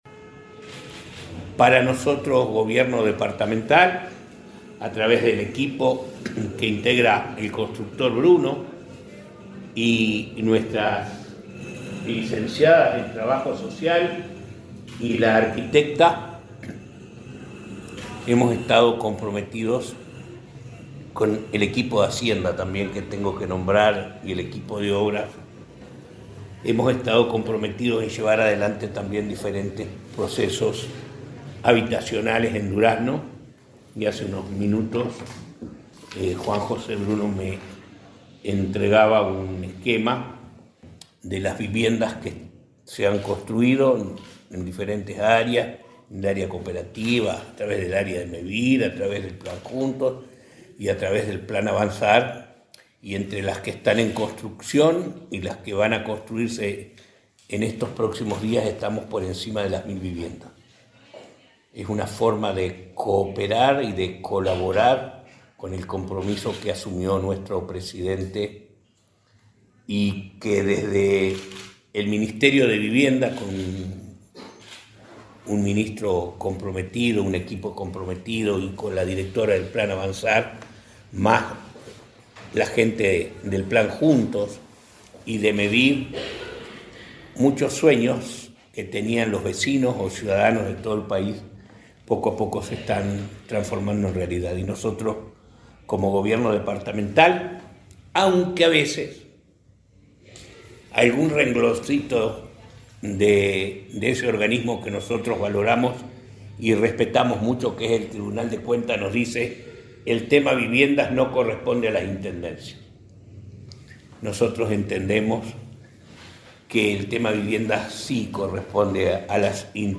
Conferencia de prensa en la Intendencia de Durazno
El intendente Carmelo Vidalín y la directora de Integración Social y Urbana del Ministerio de Vivienda, Florencia Arbeleche, brindaron una conferencia